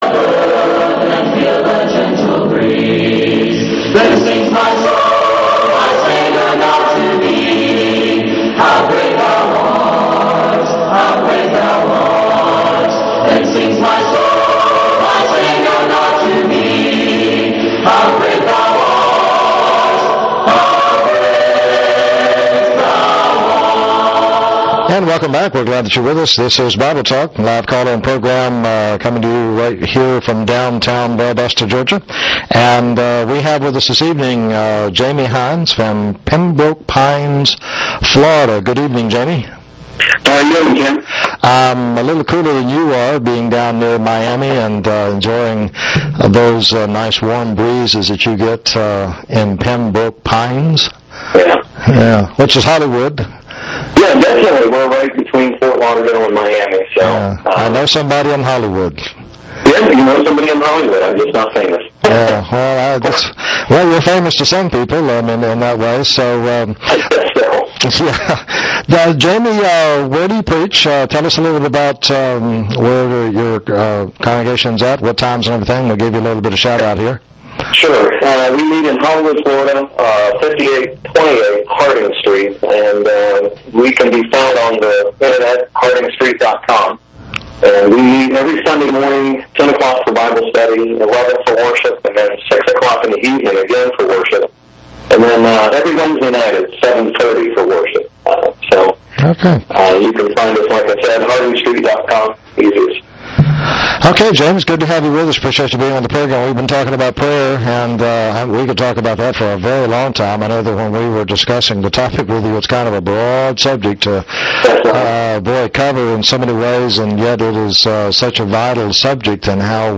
Guest panelist